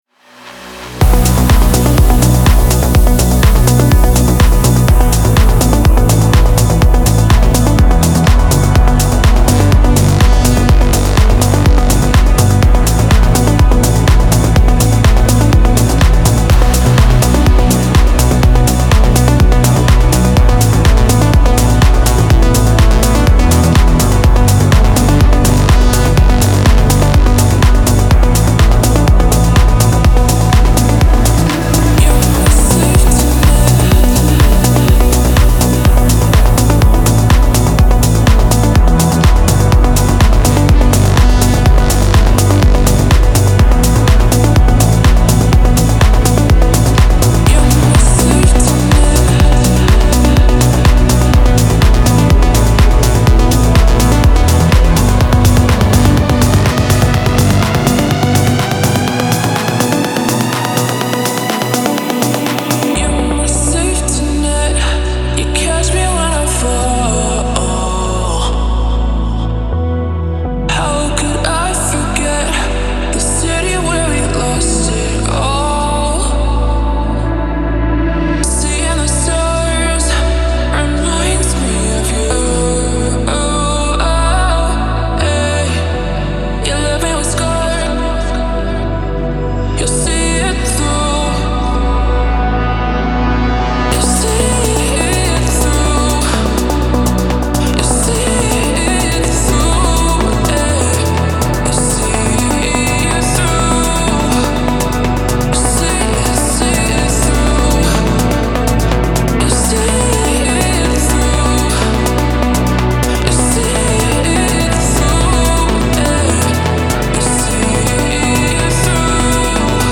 پر‌انرژی , رقص , موسیقی بی کلام , ورزشی